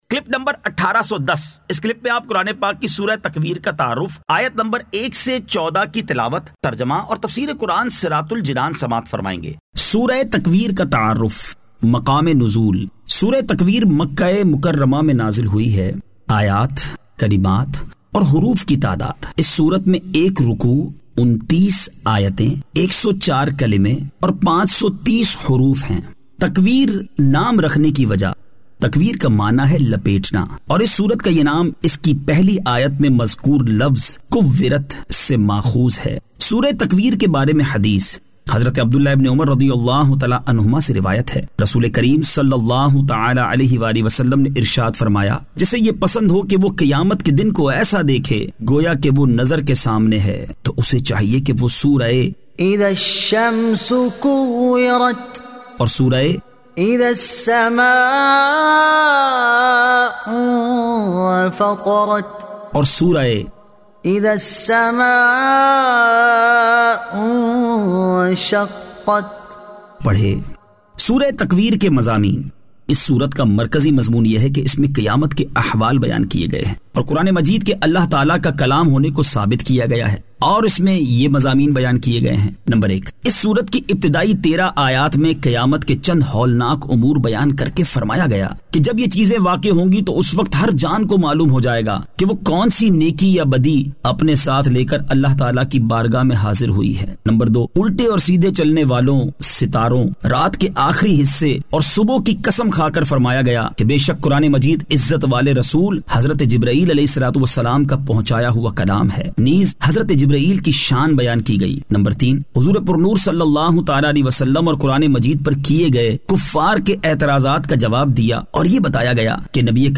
Surah At-Takwir 01 To 10 Tilawat , Tarjama , Tafseer